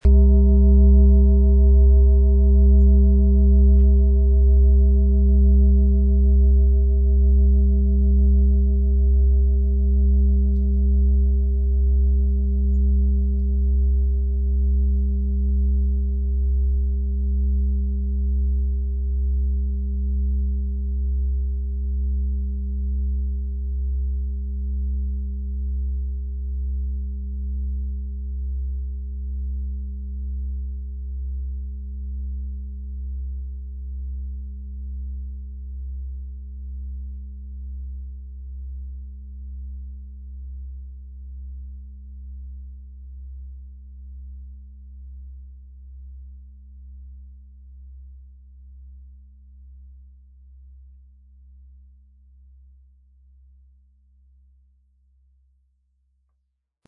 XXXL-Fußreflexzonen-Klangschale - Weite Klänge für tiefe Erdung
Klang, der in die Tiefe geht
Ein sanfter Schlag genügt, und die Schale entfaltet tiefe Töne, die dich erden und entspannen.
Sie möchten den schönen Klang dieser Schale hören? Spielen Sie bitte den Originalklang im Sound-Player - Jetzt reinhören ab.
MaterialBronze